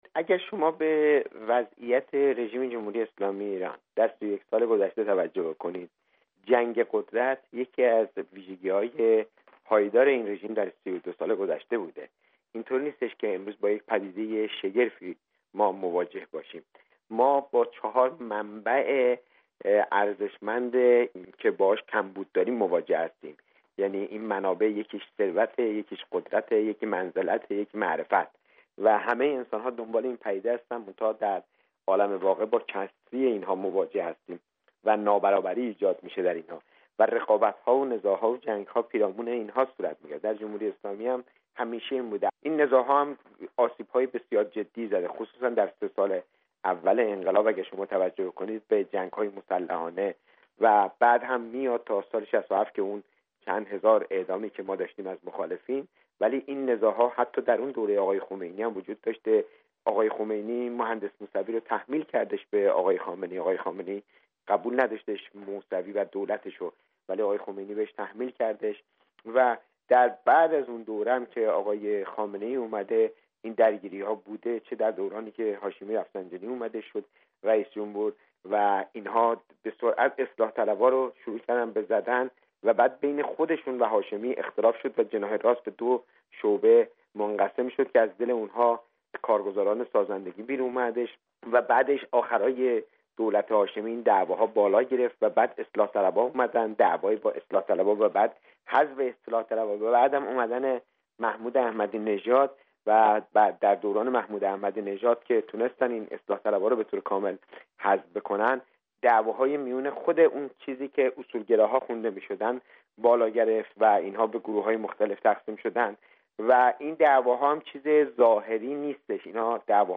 گفت و گوی ویژه رادیو فردا با اکبر گنجی درباره درخواست آیت الله خامنه ای برای وحدت جناح ها